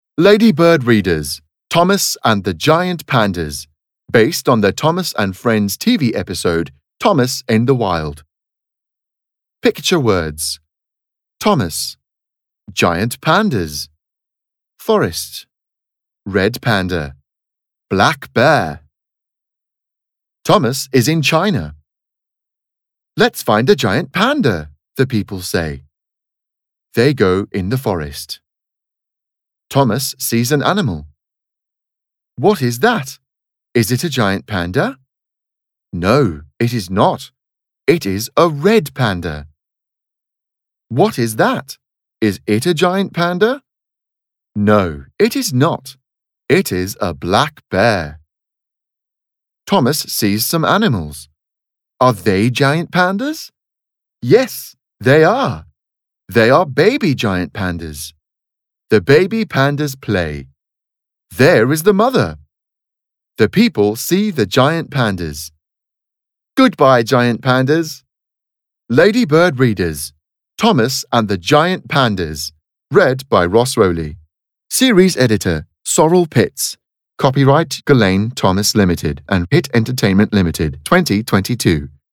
Audio UK
1.-Thomas-and-the-Giant-Pandas-Reader-UK-LADYBIRD-READERS.mp3